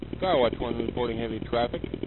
HeavyTraffic.mp3